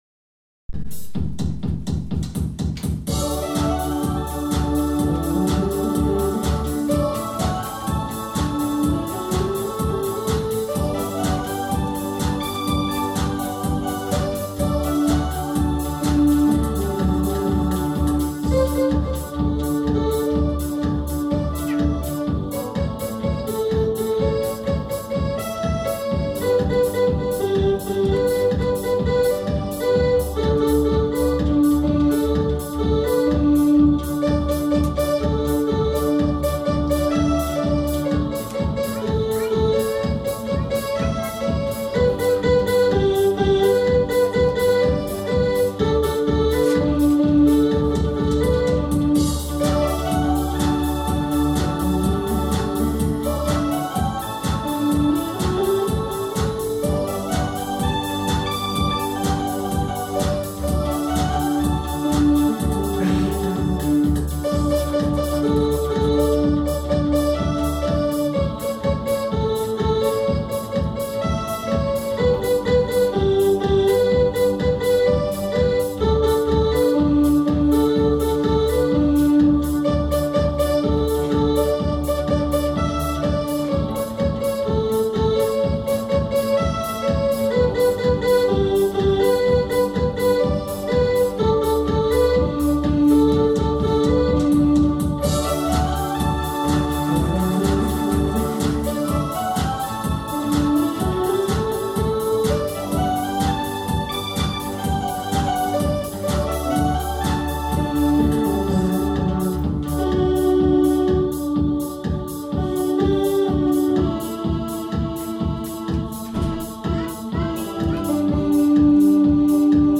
Keyboard ensemble a history of modern